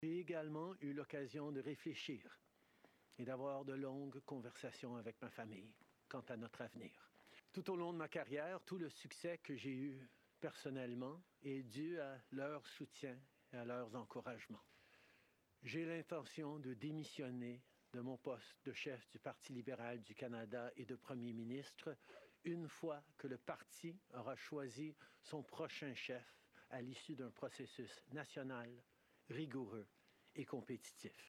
Justin Trudeau en a fait l’annonce lors d’un point de presse devant sa résidence d’Ottawa.